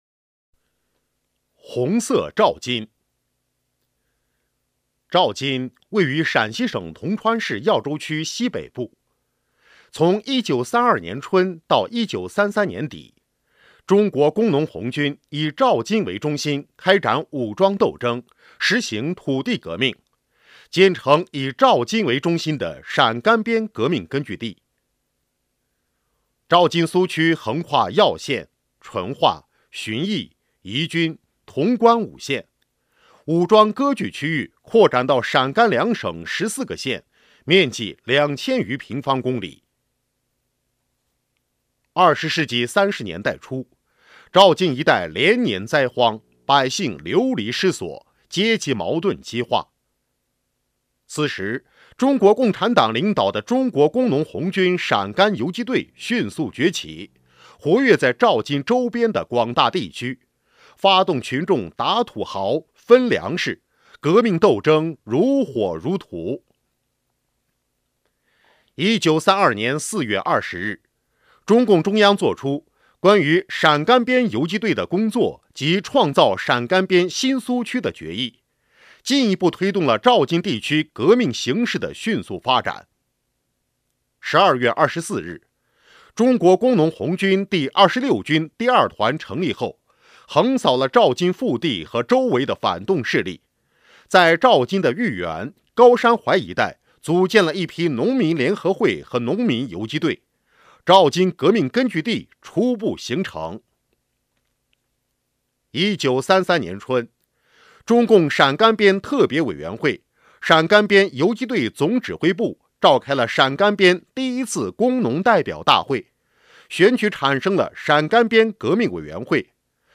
【红色档案诵读展播】红色照金